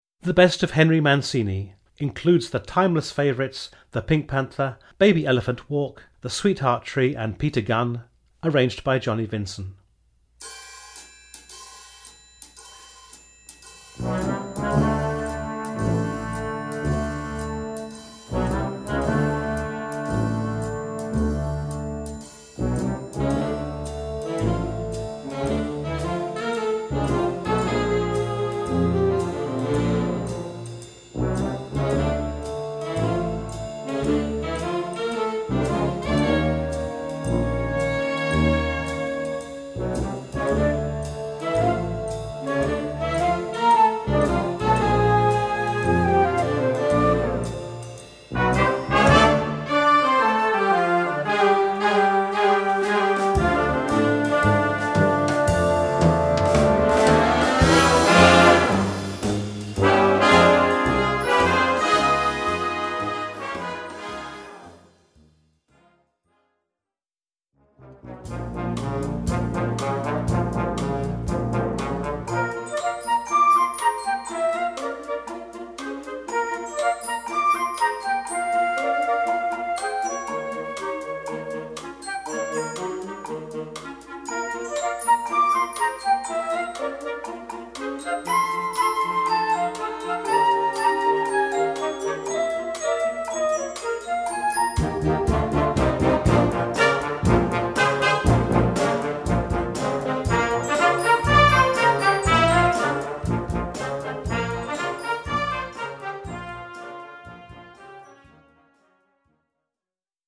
Gattung: Medley für Jugendblasorchester
Besetzung: Blasorchester